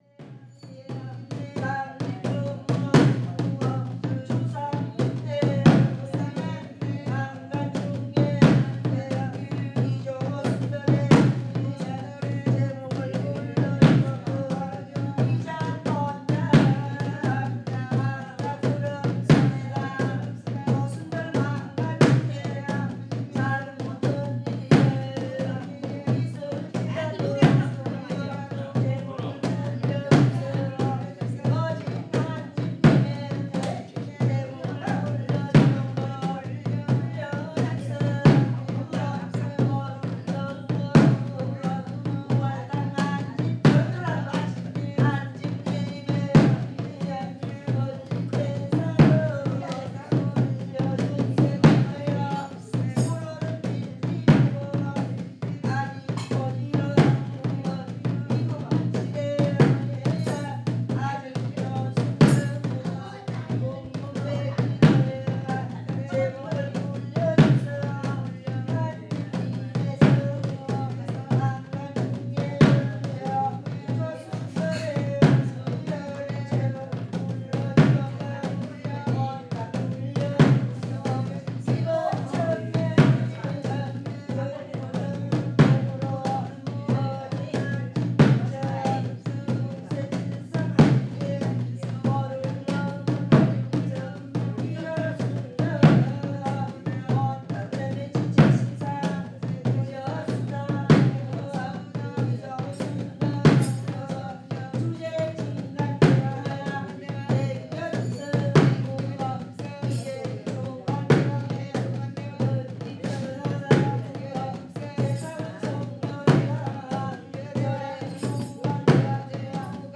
The following is a short excerpt from the field recording:
–if the sound is low on a laptop it’s better to use headphones, but careful with volume, drums, cymbals and bells.
buldomaji-music.wav